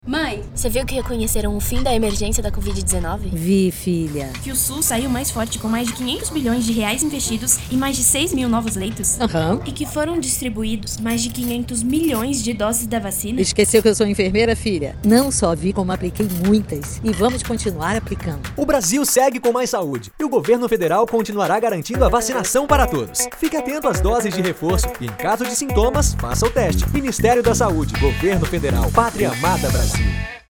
Spot - Vacinação Covid-19/SUS